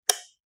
lightSwitch.wav